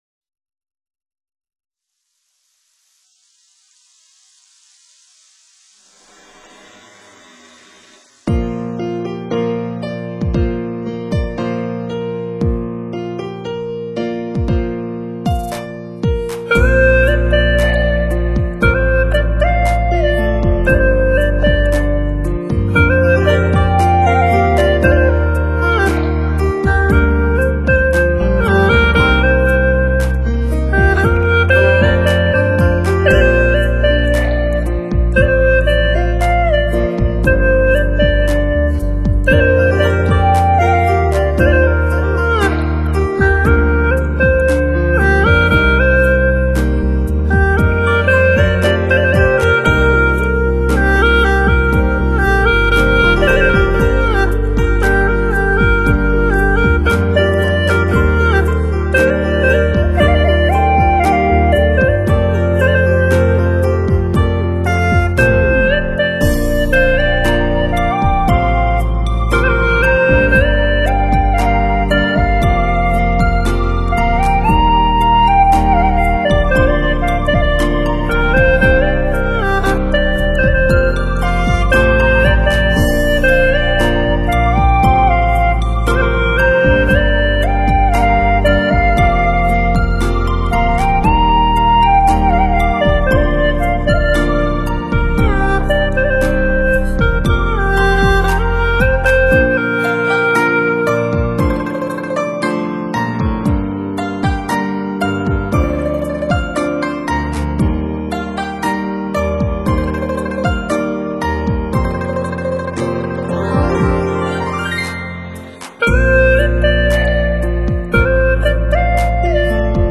葫芦丝又称葫芦箫。云南少数民族乐器。
其音色轻柔细胞，圆润质朴，曲调深情委婉，极富表现力。
作为传统乐器，它独特的音色极具新鲜感，带着醇厚浓郁的乡土气息。
本专辑是葫芦丝演奏的港台和内地流行最新歌曲，
在现代配器的支持下，和电声乐器完美地融合为一体，
同时又最大限度地展现了葫芦丝原汁原味的民族特色。